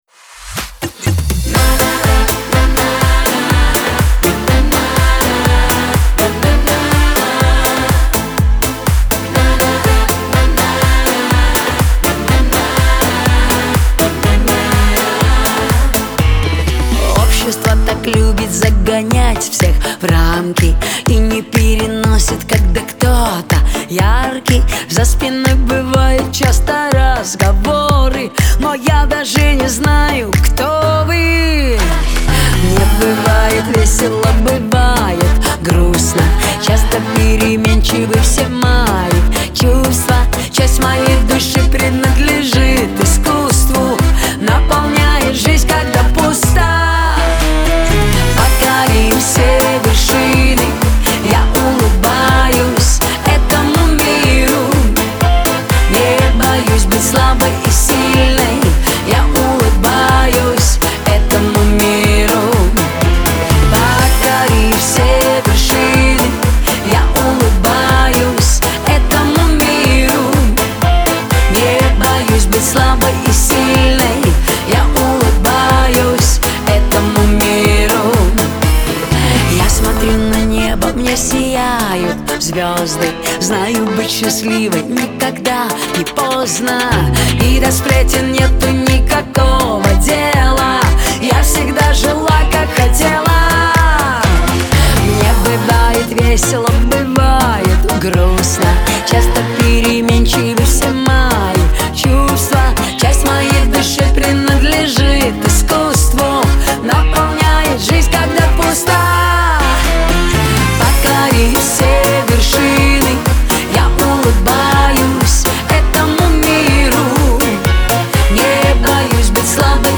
pop , диско
весёлая музыка , эстрада